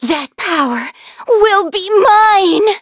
Xerah-Vox_Skill6_b.wav